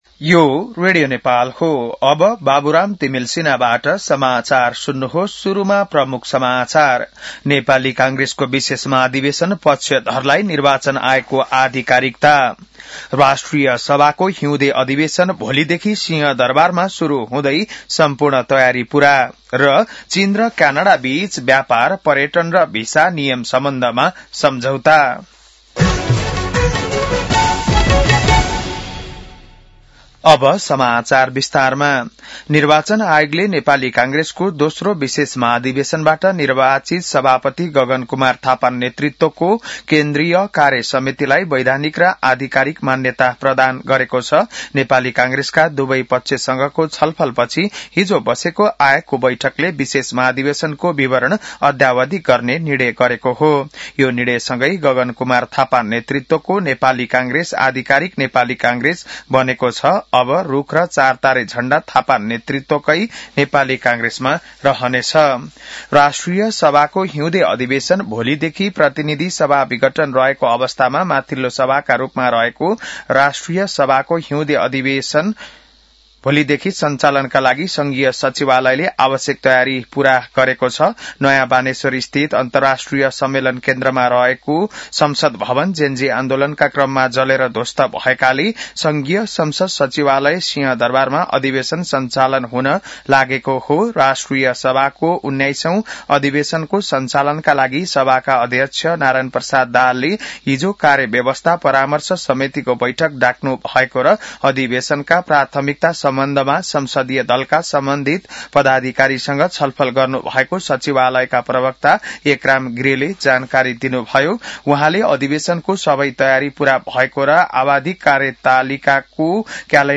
बिहान ९ बजेको नेपाली समाचार : ३ माघ , २०८२